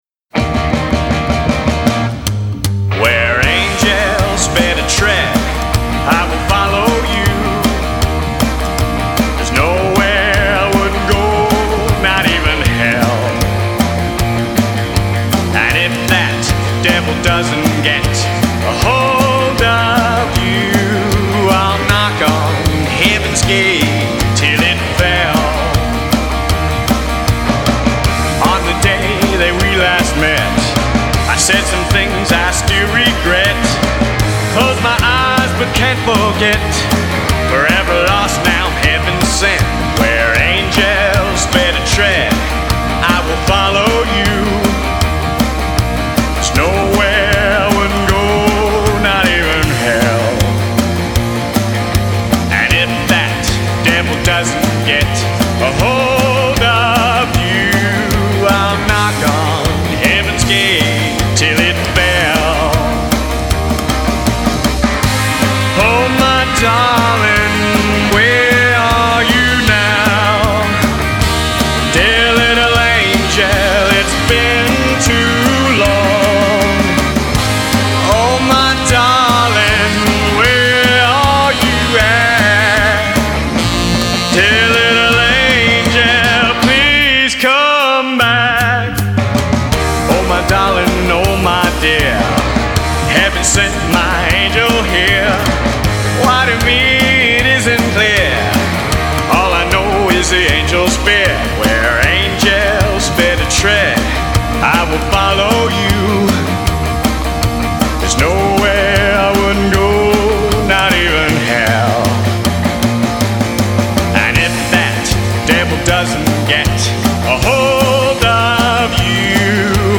Vocals
Bass
Drums